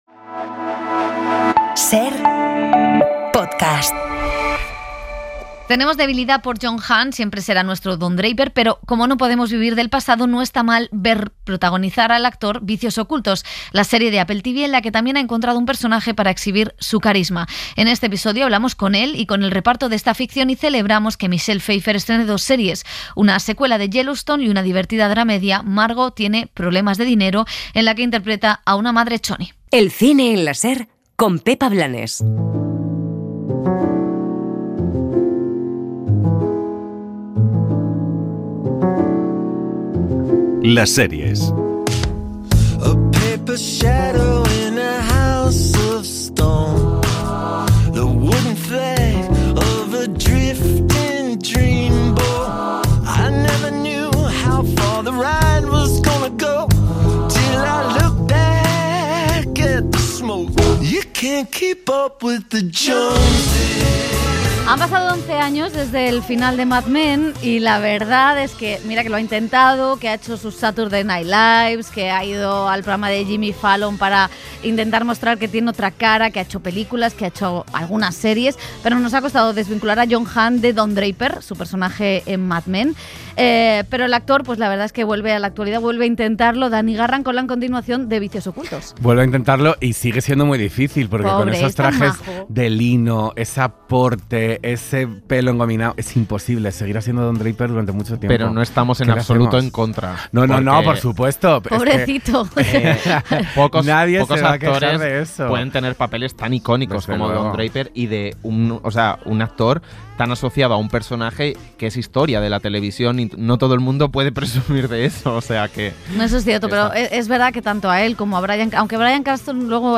En este episodio charlamos con el actor y el reparto de esta ficción, y también celebramos que Michelle Pfeiffer estrene dos series, una secuela de 'Yellowstone' y una divertida dramedia, 'Margo tiene problemas de dinero', en la que interpretaba a una madre choni.